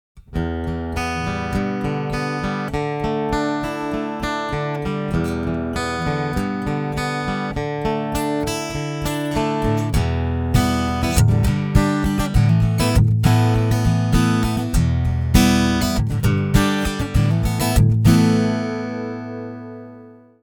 Ich beschränke mich auf die Beurteilung dessen, was beim Publikum ankommt: Den gnadenlosen Piezo – Tonabnehmersound.
Alle meine Gitarren, die von der Bauart als Akustikgitarren durchgehen oder vom Hersteller als „klingt wie eine Akustikgitarre“ beworben werden habe ich direkt an ein Audiointerface angeschlossen und ohne jeden Effekt und ohne EQ direkt mit dem Rechner aufgenommen.
SeagullQ2mic.mp3